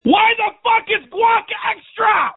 Screams from December 26, 2020
• When you call, we record you making sounds. Hopefully screaming.